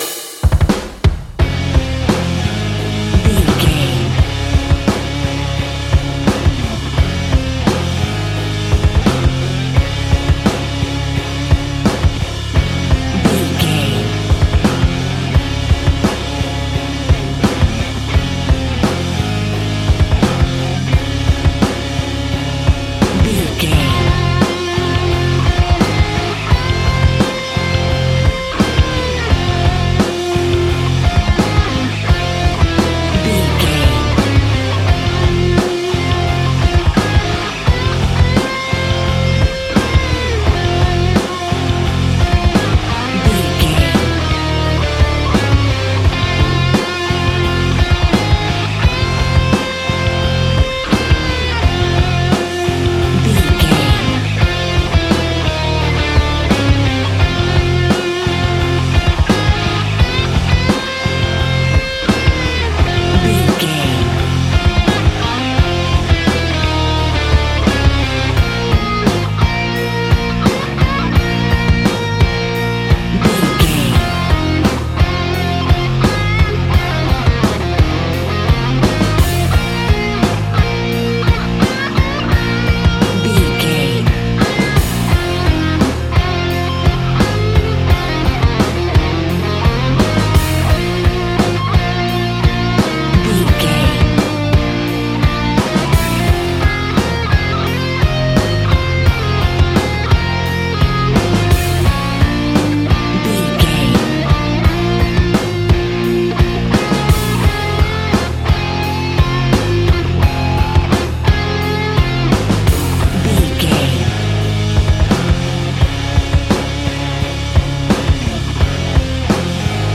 Aeolian/Minor
angry
heavy
aggressive
electric guitar
drums
bass guitar